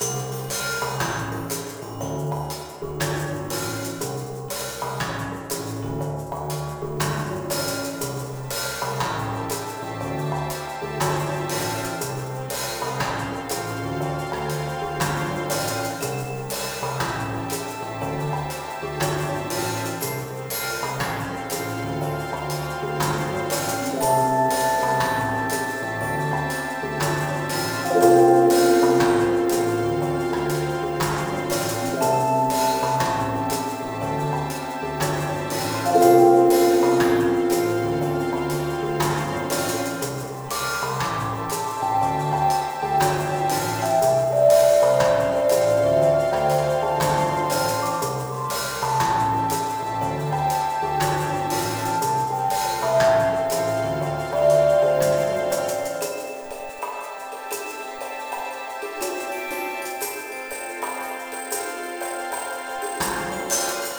calming music is heard playing on the intercom.